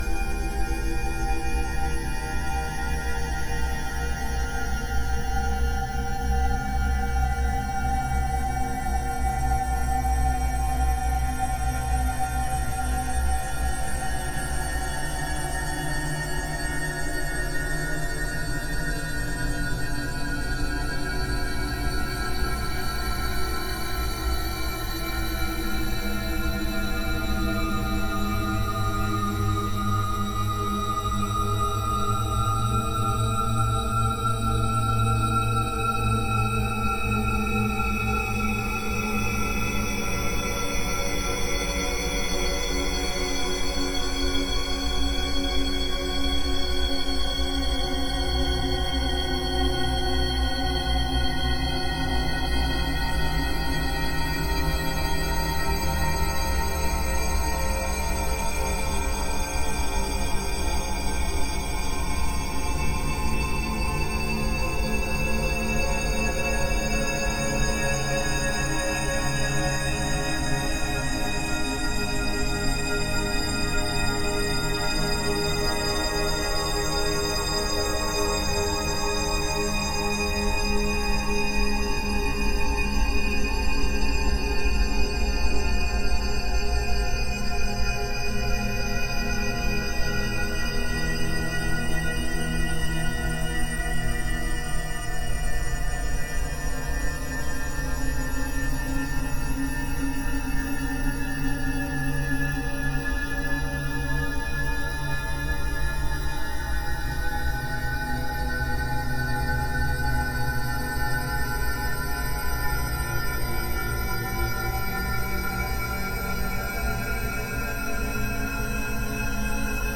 horror ambience 13 Sound Effect — Free Download | Funny Sound Effects